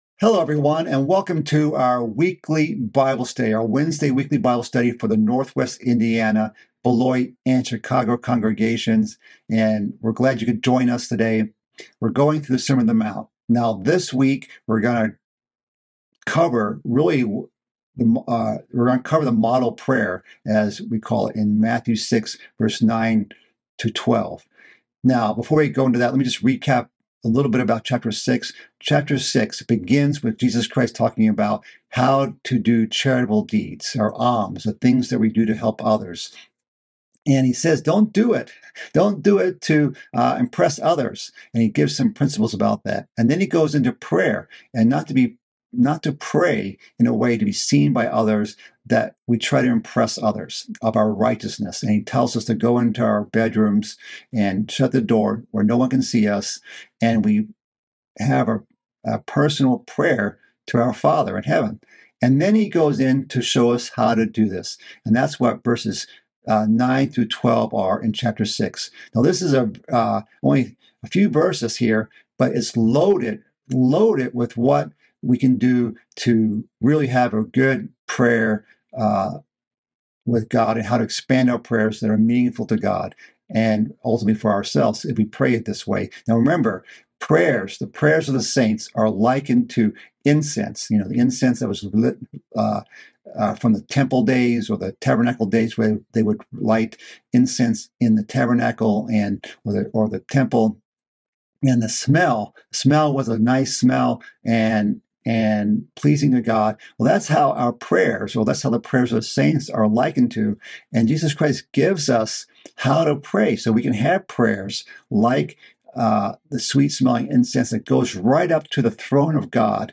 This is part of a mid-week Bible study series covering the sermon on the mount. This study continues on in the section on how to pray, when Christ gives us the outline of a good prayer.